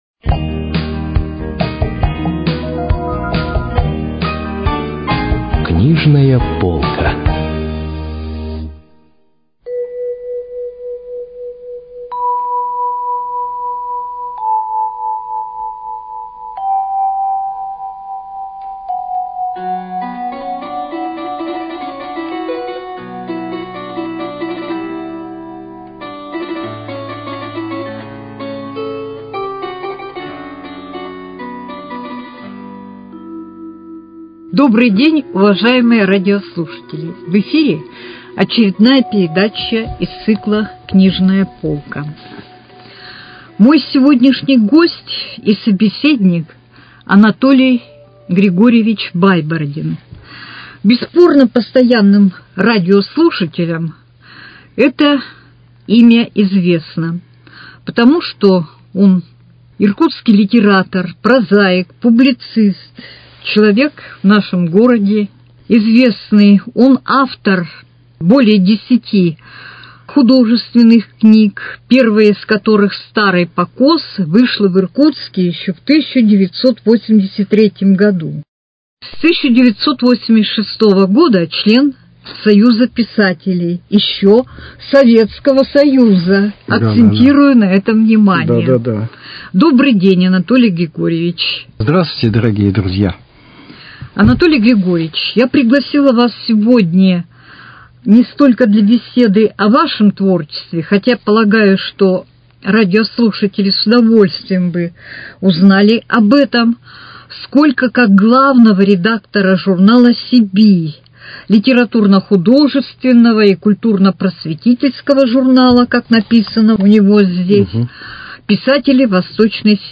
Ведущая и писатель ведут разговор о творчестве драматурга Александра Вампилова.